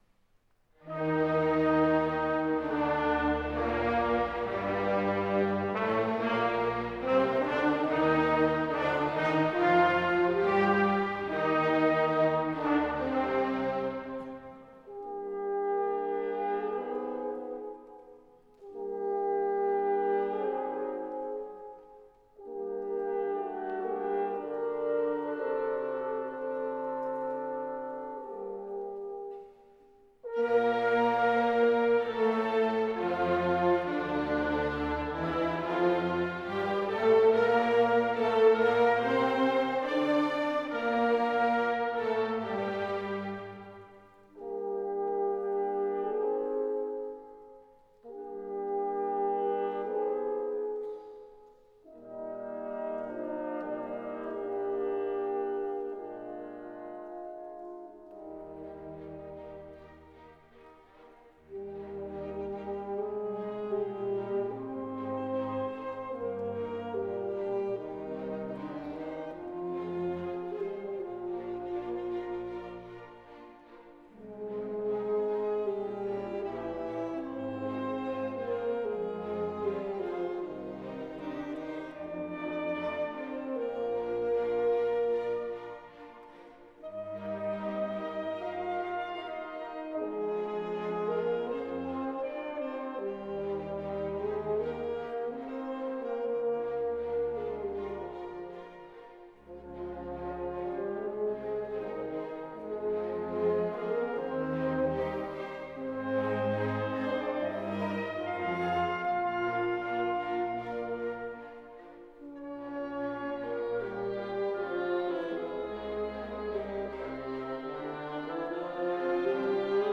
Orquestra Universitat de Barcelona
Keywords: Concerts